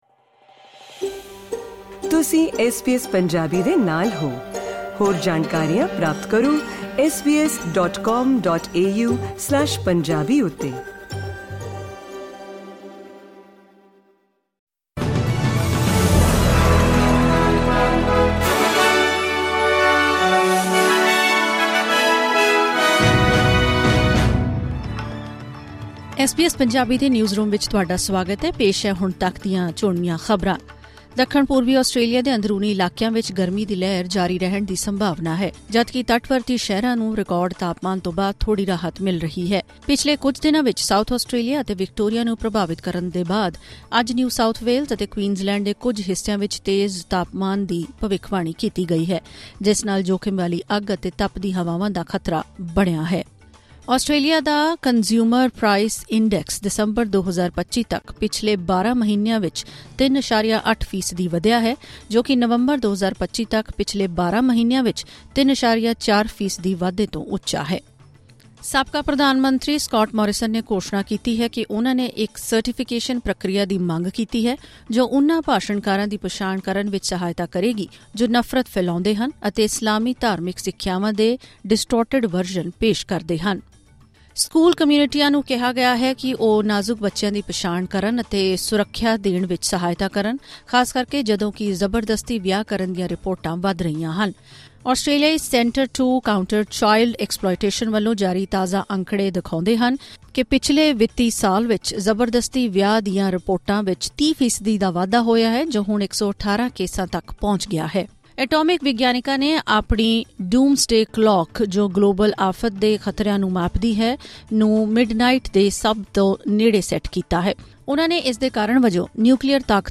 ਖ਼ਬਰਨਾਮਾ: ਦੱਖਣ-ਪੂਰਬੀ ਆਸਟ੍ਰੇਲੀਆ ‘ਚ ਗਰਮੀ ਦੀ ਲਹਿਰ, ਨਿਊ ਸਾਊਥਵੇਲਜ਼ ਅਤੇ ਕਵੀਨਜ਼ਲੈਂਡ ‘ਚ ਸੰਭਾਵਿਤ ਅੱਗ ਦਾ ਖ਼ਤਰਾ